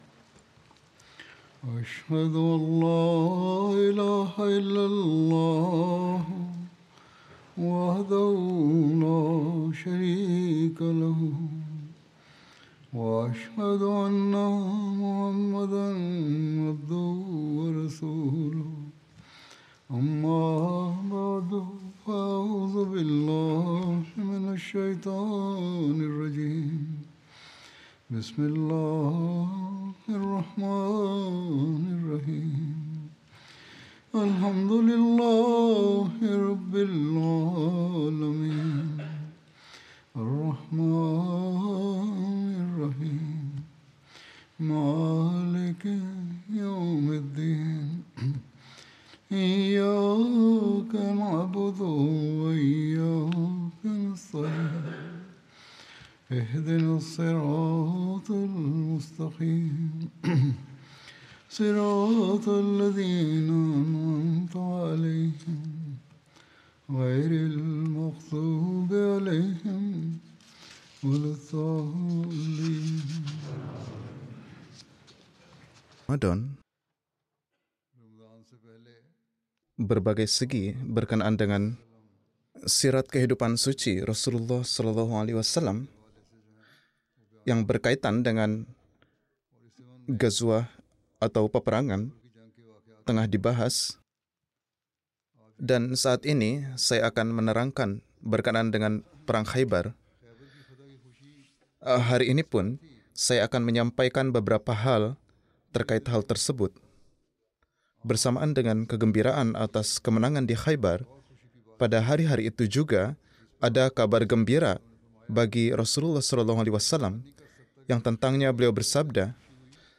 Indonesian Translation of Friday Sermon delivered by Khalifatul Masih